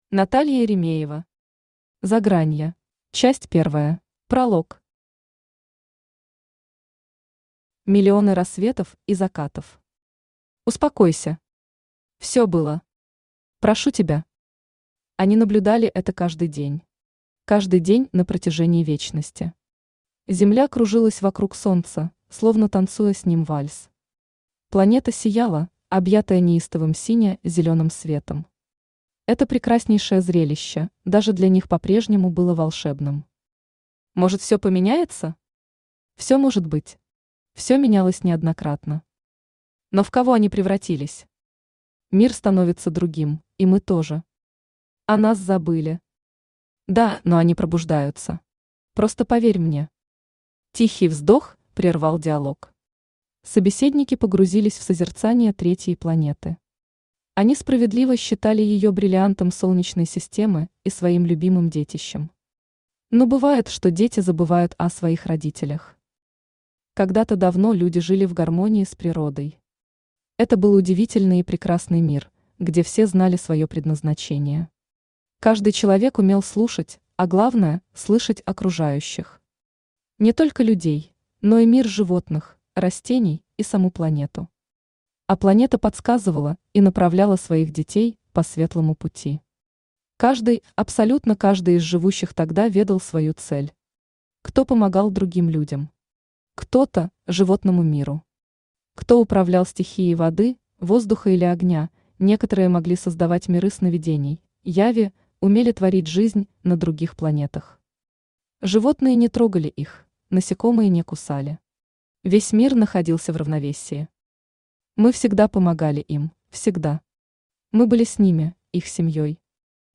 Aудиокнига Загранье Автор Наталья Еремеева Читает аудиокнигу Авточтец ЛитРес.